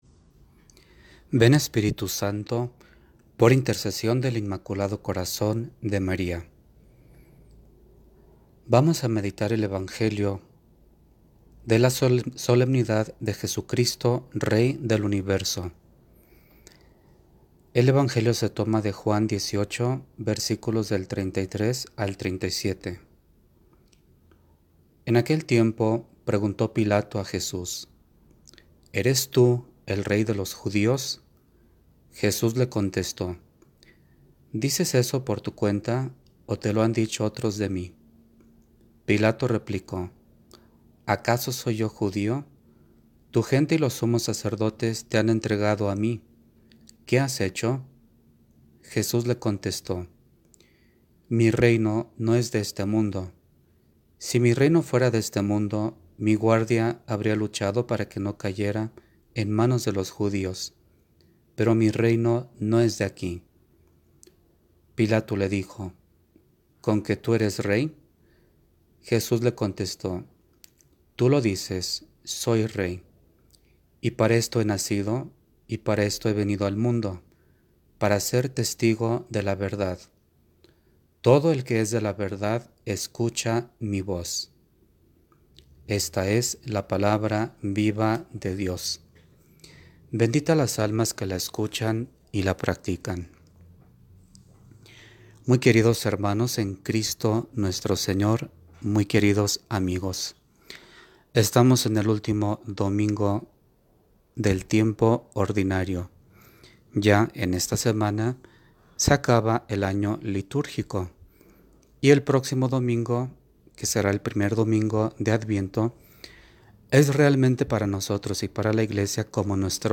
PRÉDICAS EN AUDIO. Festividad de Jesucristo, Rey del Universo - Como Vara de Almendro